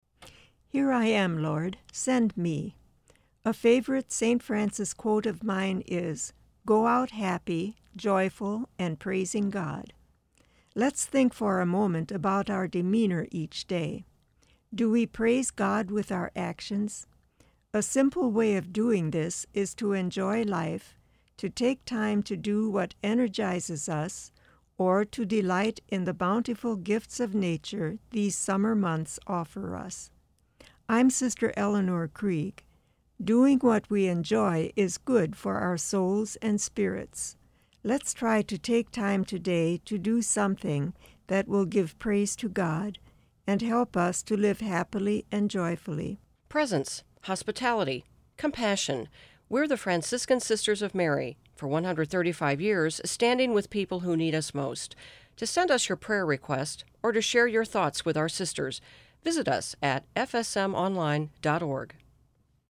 Each month, FSM share a one-minute message of hope, joy, grace or encouragement on the St. Louis classical music radio station.